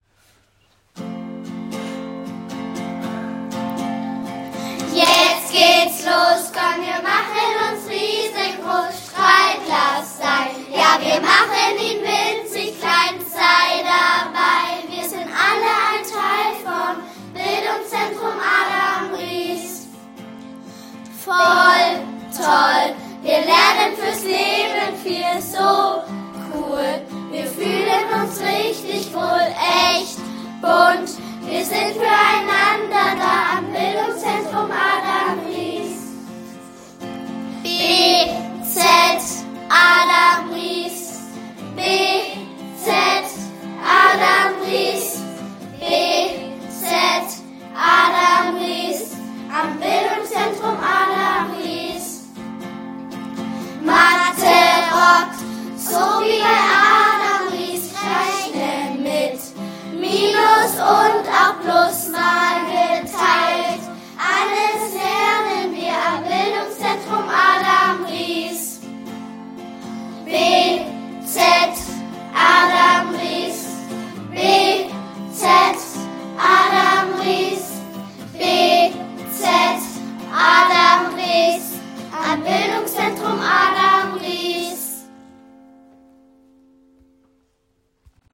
Die Melodie ist lebendig und eingängig, sodass sie leicht mitzusingen ist.
Hörprobe unseres Chores